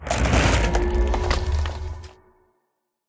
Minecraft Version Minecraft Version snapshot Latest Release | Latest Snapshot snapshot / assets / minecraft / sounds / block / respawn_anchor / set_spawn1.ogg Compare With Compare With Latest Release | Latest Snapshot